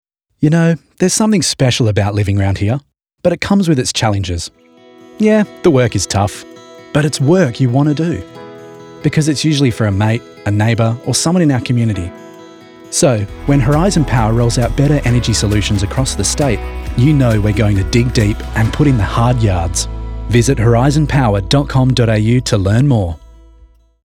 Male
Yng Adult (18-29), Adult (30-50)
Radio Commercials
Government - Conversational
All our voice actors have professional broadcast quality recording studios.